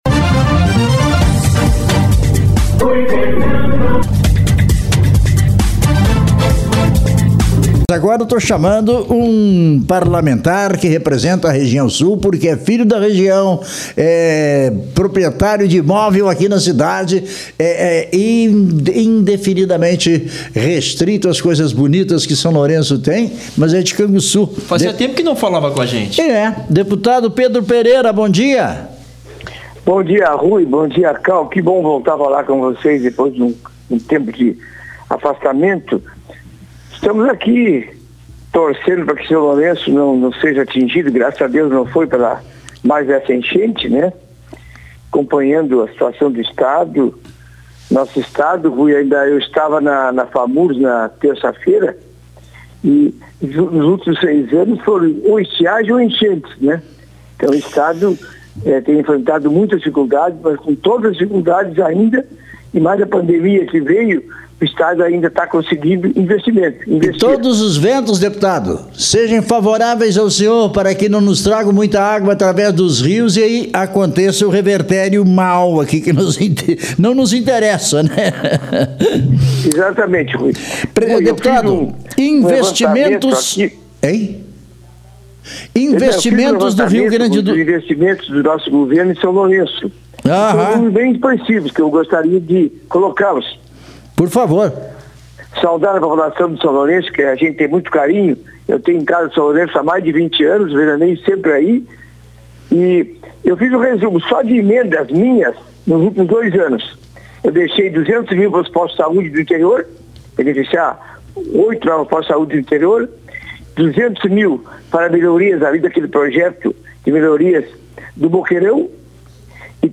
Entrevista com o Deputado Estadual Pedro Pereira (PSDB)